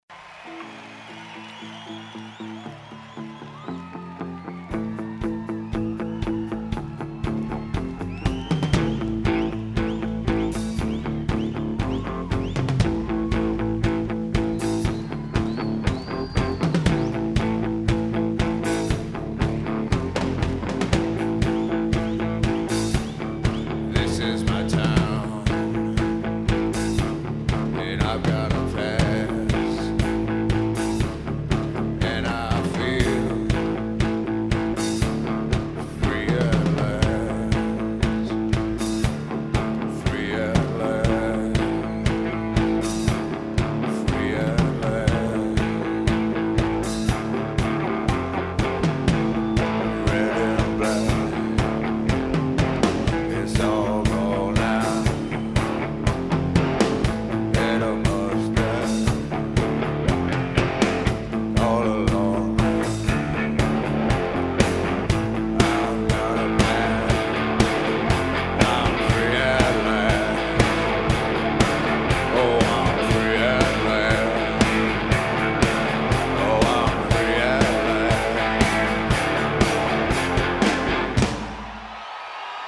Freiluftbuehne Wuhlheide: Berlin, Germany